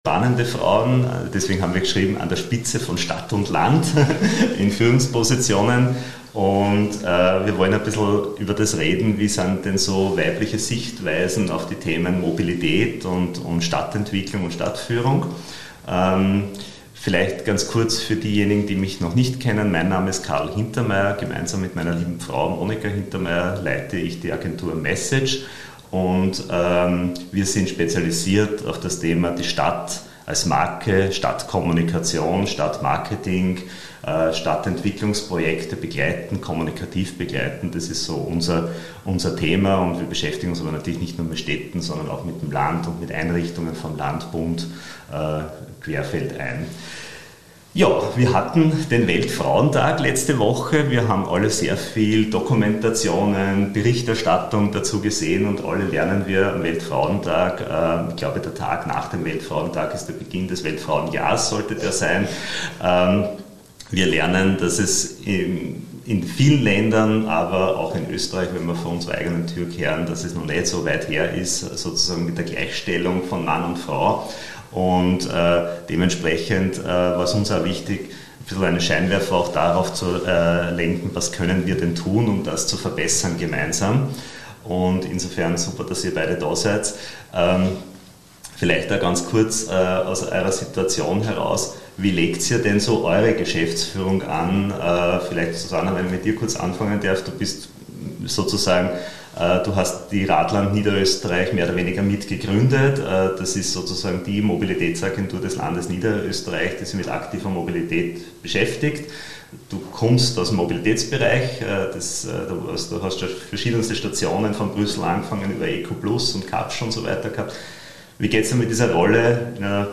Wir freuen uns sehr, dass wir gleich zwei Powerfrauen zum Frühstückstalk gewinnen konnten, um mit ihnen über das so wichtige und drängende Thema der Gleichstellung zu sprechen.